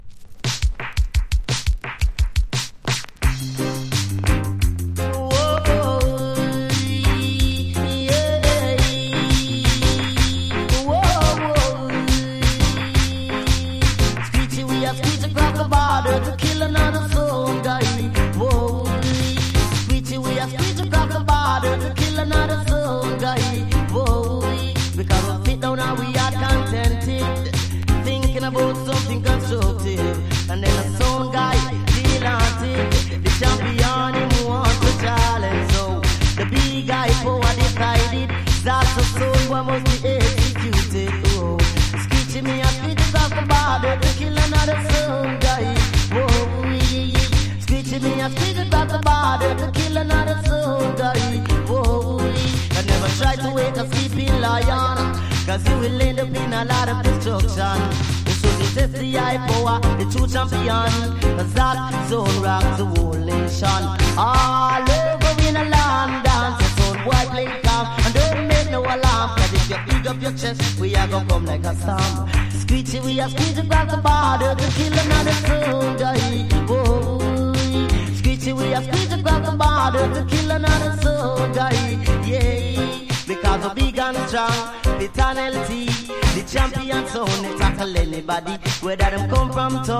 • REGGAE-SKA
DANCE HALL
所によりノイズありますが、リスニング用としては問題く、中古盤として標準的なコンディション。